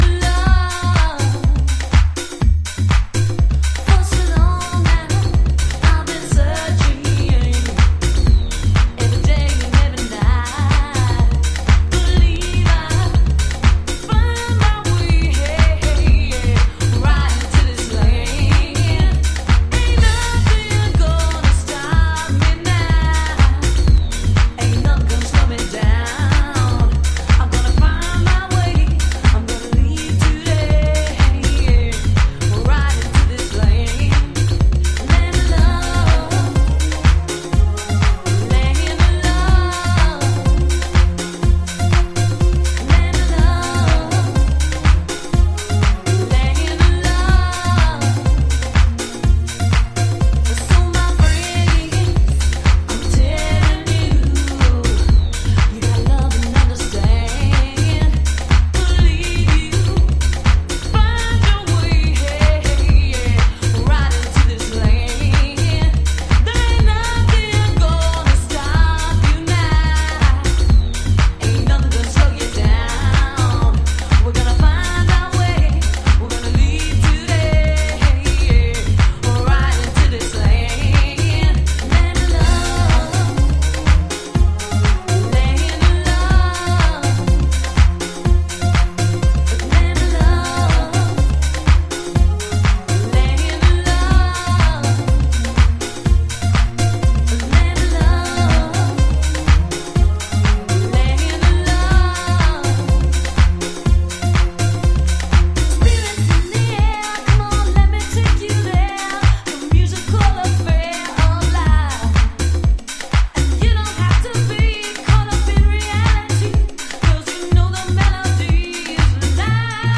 House / Techno
ベースが強調されたディープハウス〜ガラージハウス。